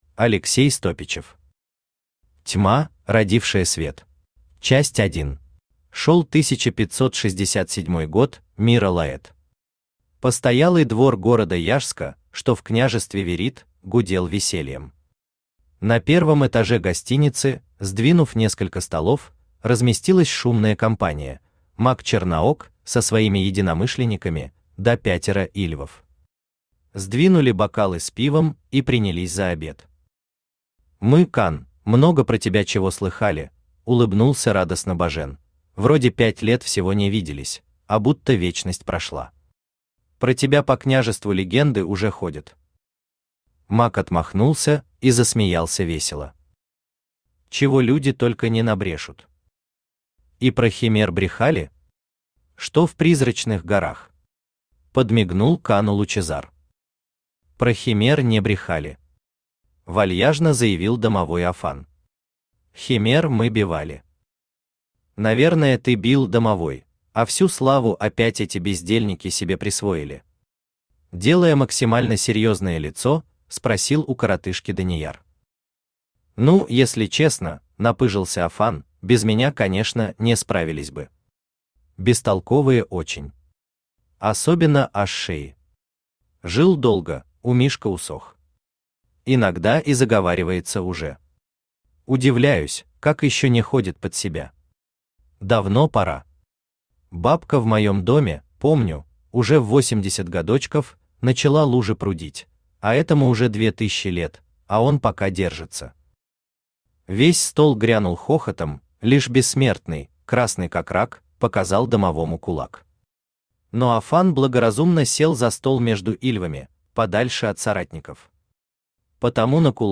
Аудиокнига Тьма, родившая свет | Библиотека аудиокниг
Aудиокнига Тьма, родившая свет Автор Алексей Александрович Стопичев Читает аудиокнигу Авточтец ЛитРес.